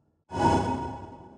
Trimmed-Holy Buffs
sfx updates